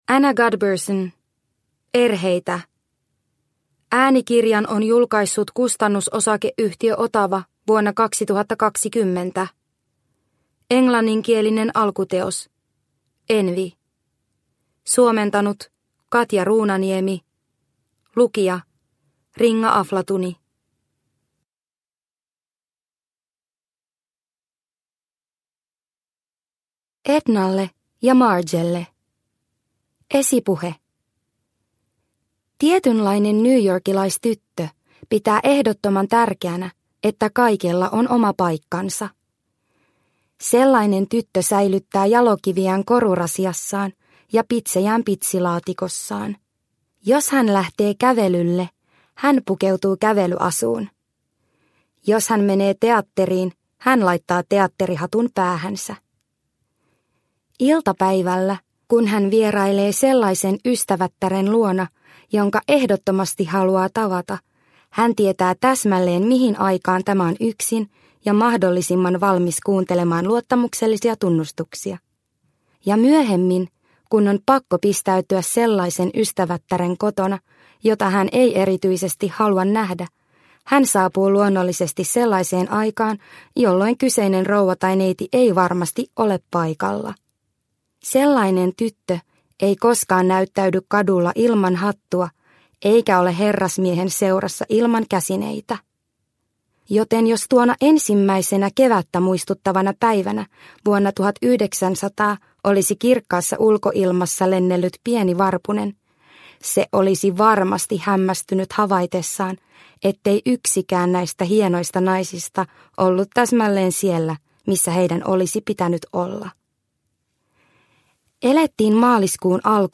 Erheitä – Ljudbok – Laddas ner